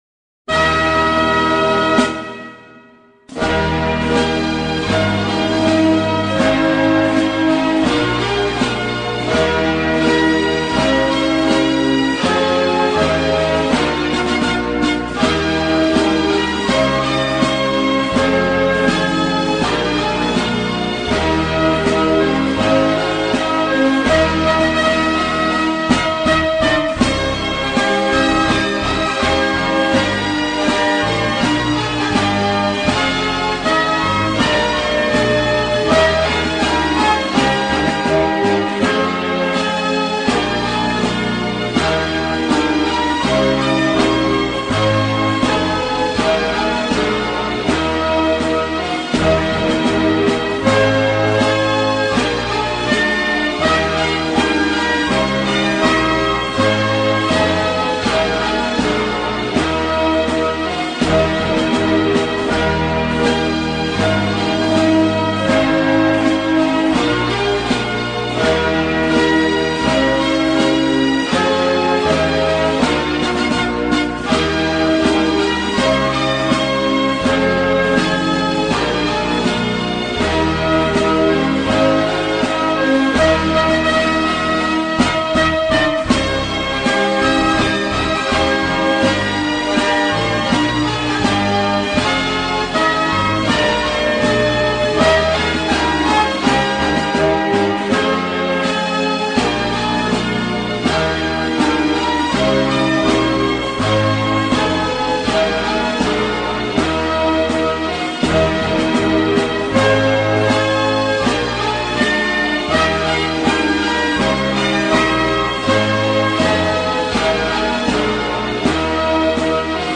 Инструментальная версия гимна